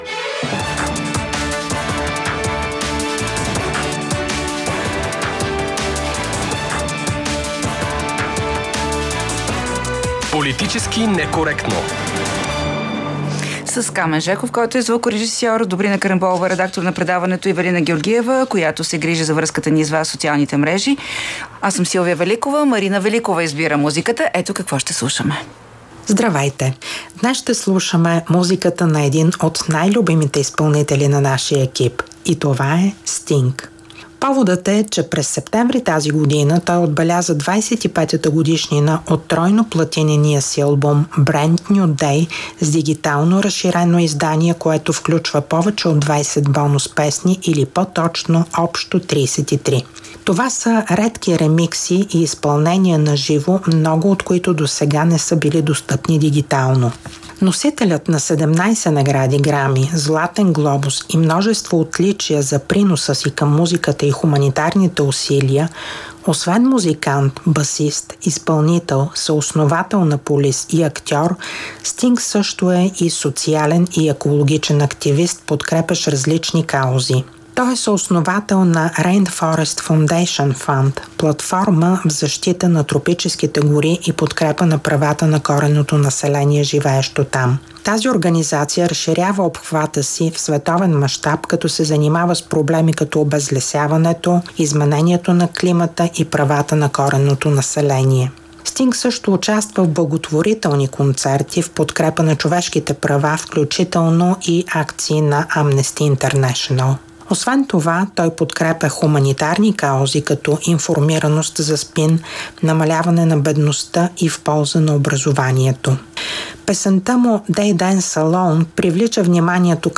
▪ Гост е Явор Божанков, който две години след като беше изключен от ПГ на БСП /заради различната си позиция за войната в Украйна/, беше на косъм да бъде изключен и от ПП-ДБ /заради различното му гласуване за председател на 51-вото Народно събрание/.